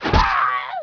archer_die.wav